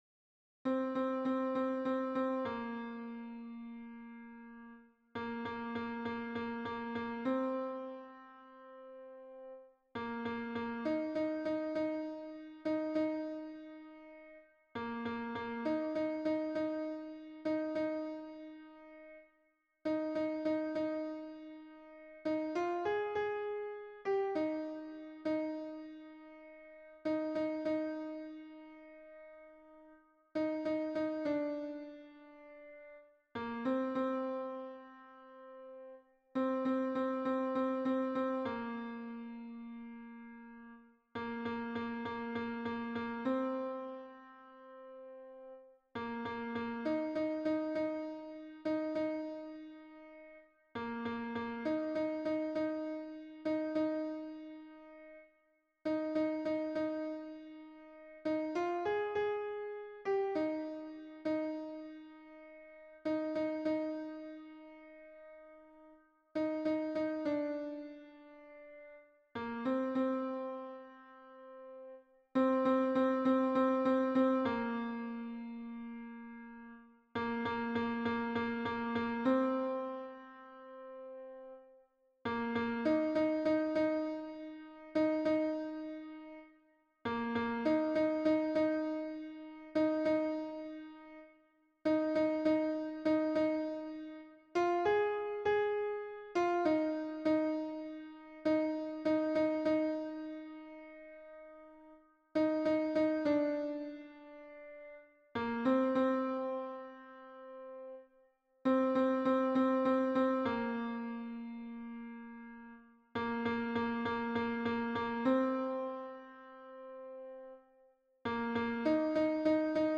MP3 version piano - 3 voix (pour la chorale)
Alto